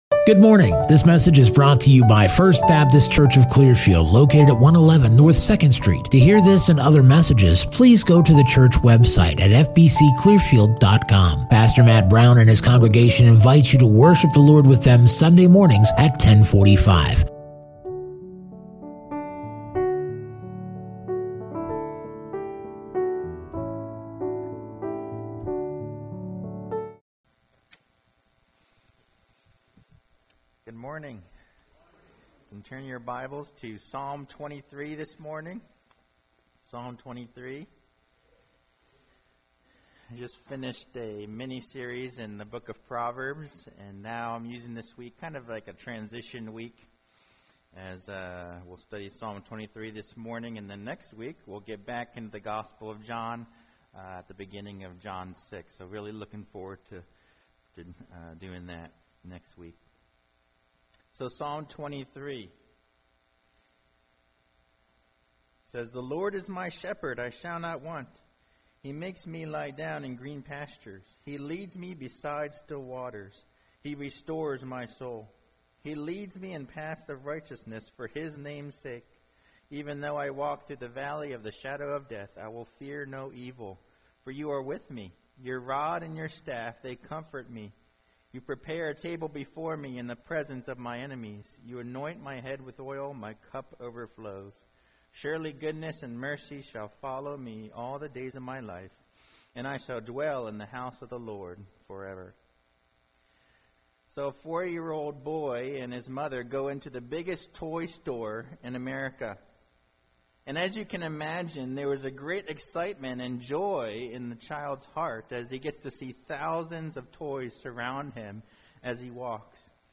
Sermon Passage: Psalm 23